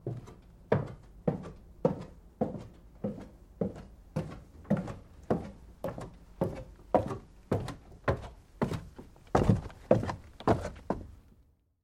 Звуки колокольни
Звук шагов: подъем по лестнице к колоколу